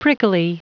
Prononciation du mot prickly en anglais (fichier audio)
Prononciation du mot : prickly